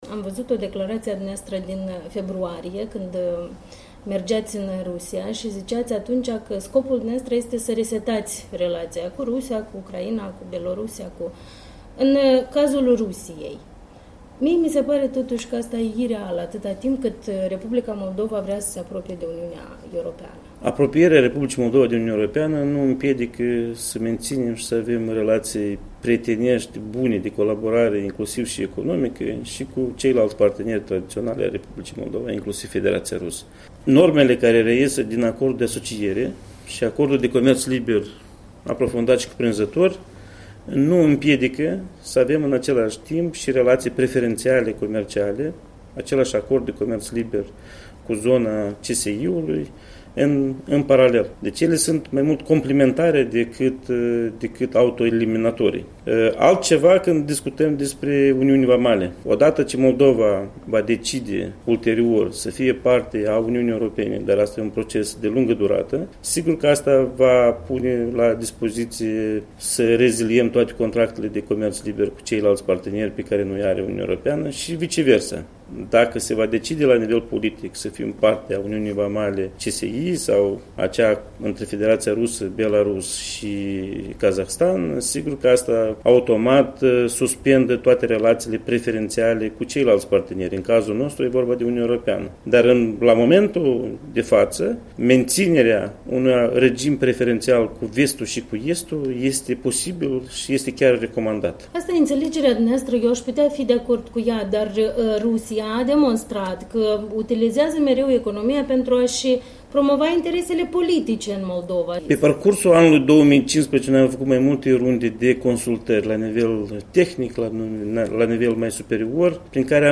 Interviu cu Octavian Calmîc